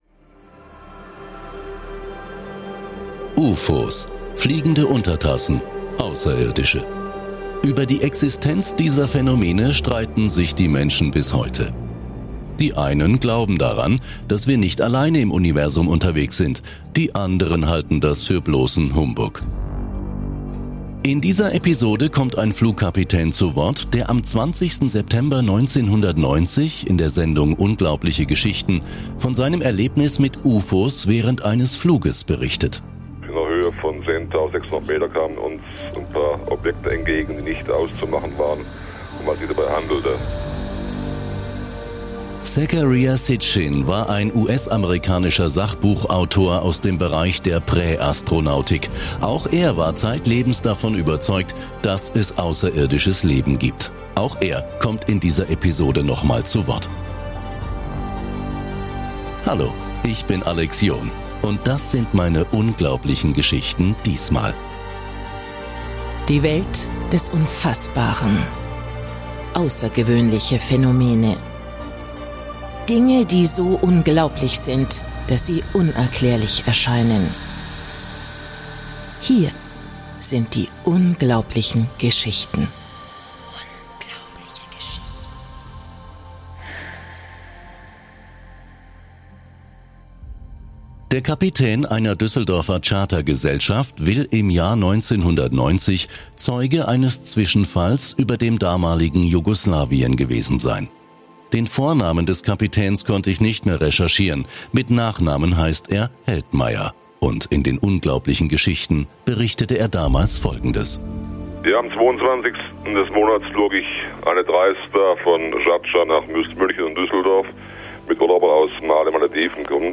Zu Gast im Studio der Unglaublichen Geschichten war seinerzeit der Astrophysiker Johannes von Buttlar.
Das war eine Rubrik innerhalb der Unglaublichen Geschichten, die damals im Radio liefen. Diesmal geht es um Traumreisen. Und zum Schluss hören Sie eine geführte Meditation.